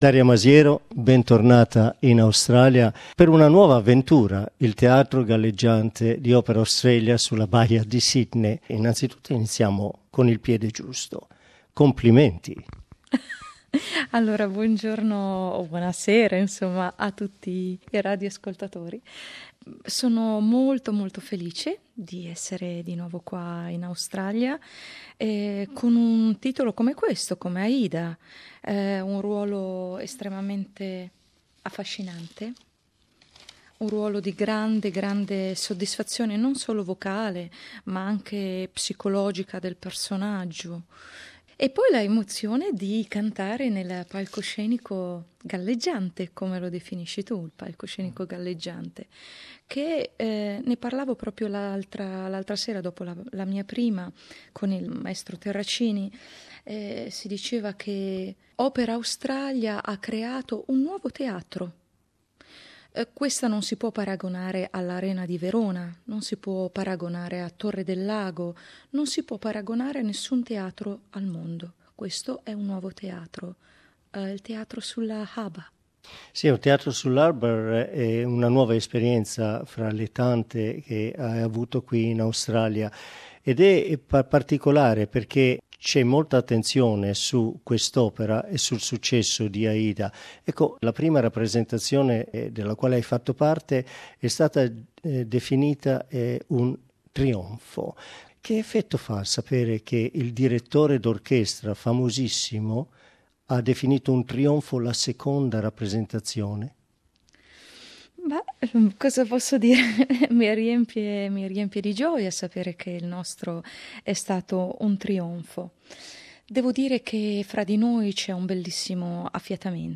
Our interview